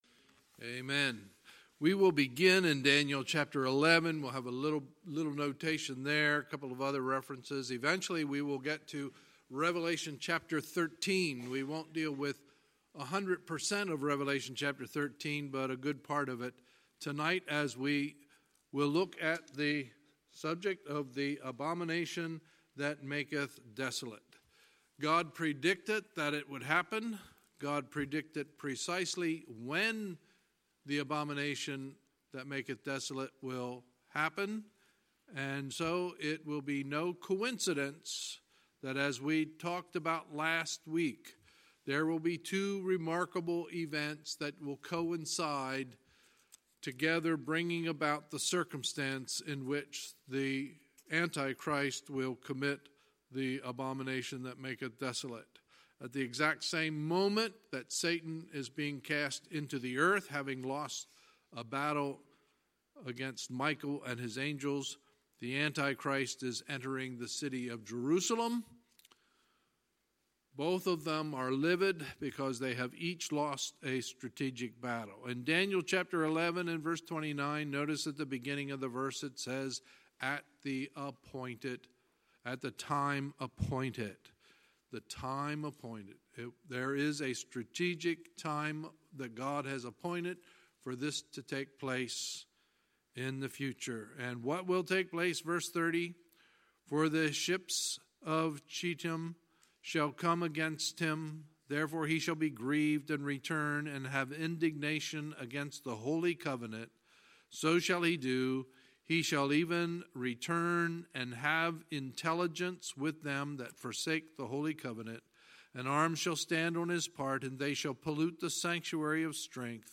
Sunday, March 31, 2019 – Sunday Evening Service